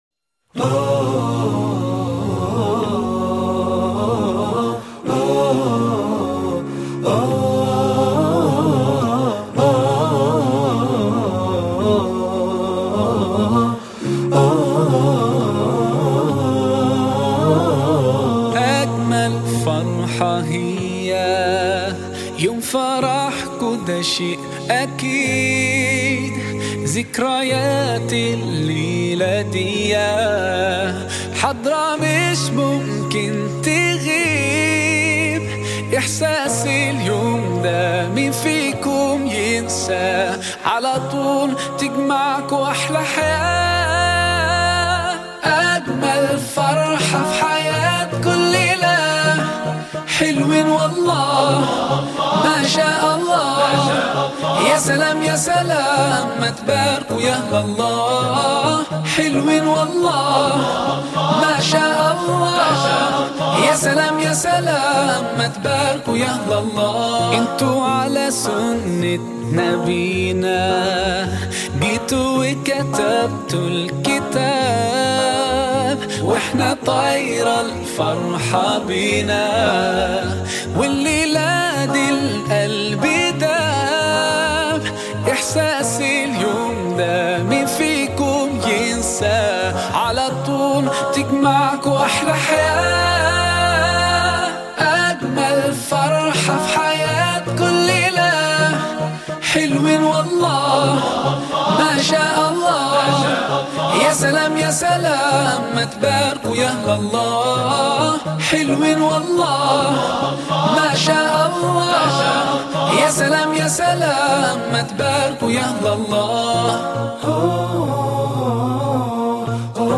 دسته : موسیقی ملل